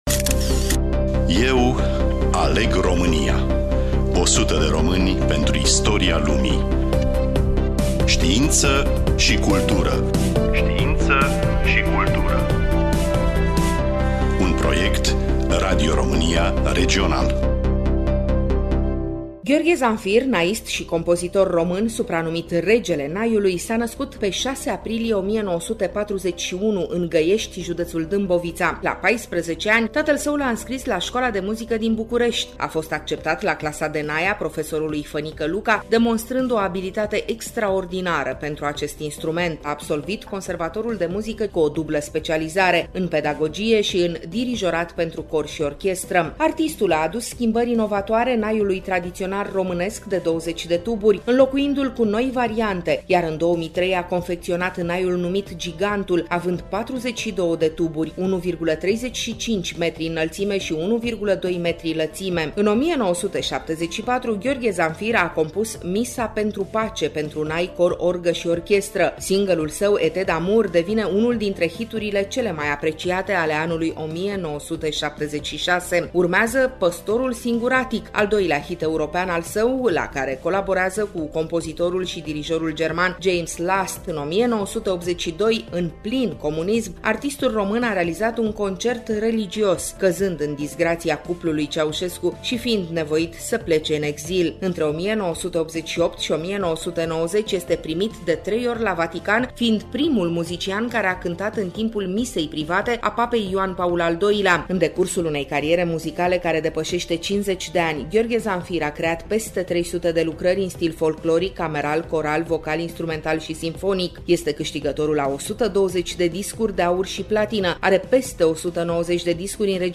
Studioul: Radio Romania Oltenia-Craiova